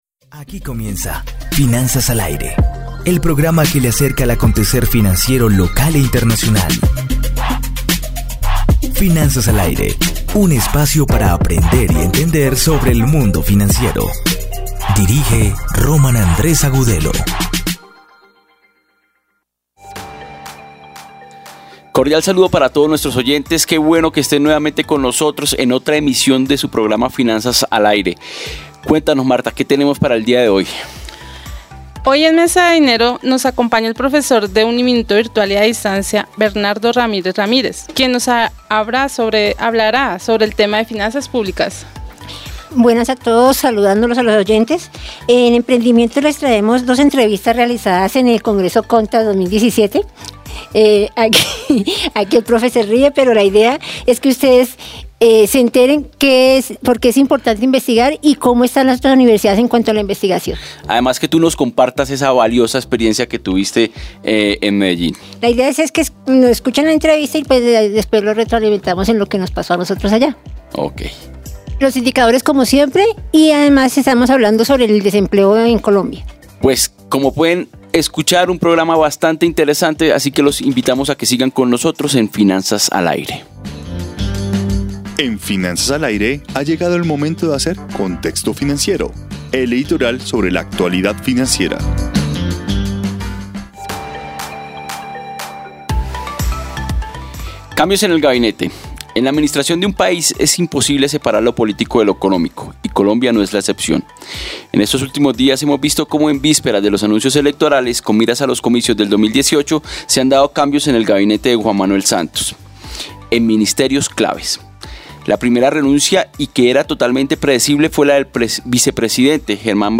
En Emprendimiento les traemos dos entrevistas realizadas en el Congreso CONTAB 2017 en la ciudad de Medellín